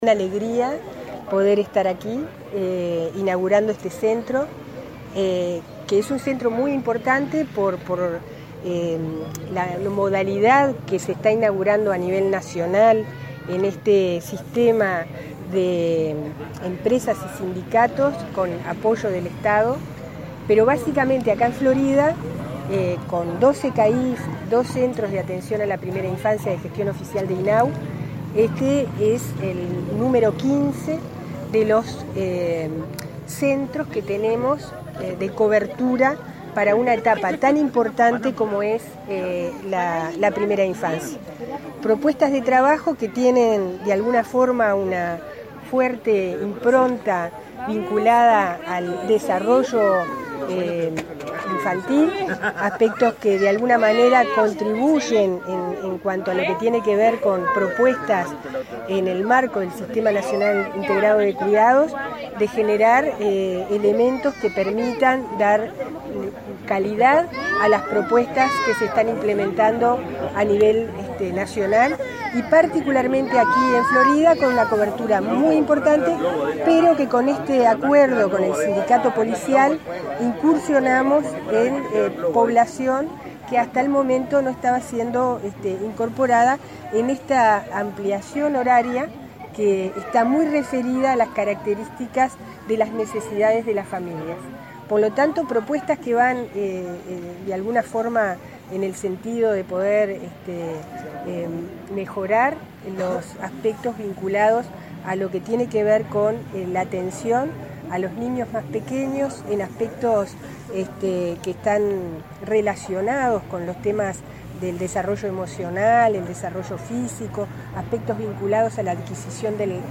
La presidenta de INAU, Marisa Lindner, dijo tras la inauguración este lunes de un centro de cuidados para hijos de policías en Florida, en conjunto con empresa y sindicato, que este es el centro número 15 de cobertura para la primera infancia en ese departamento. Recordó que el plan CAIF atiende a 56 mil niños a través de 422 centros.